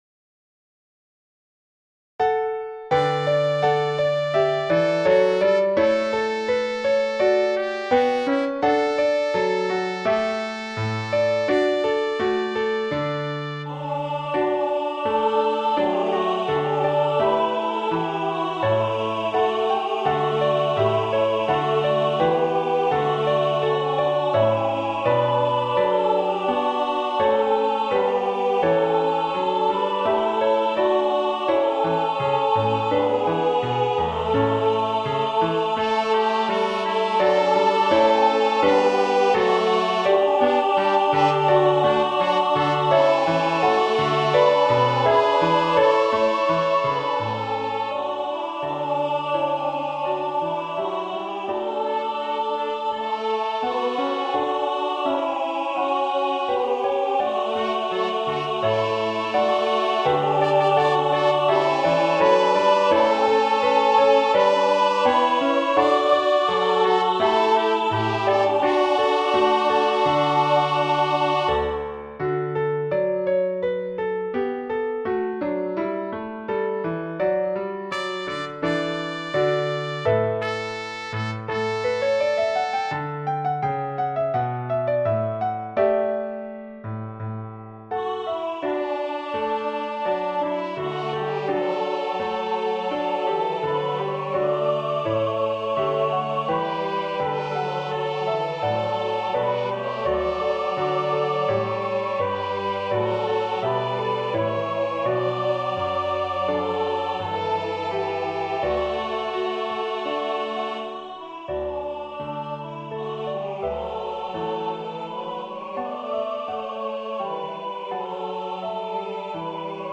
Choral
Vocal
Christmas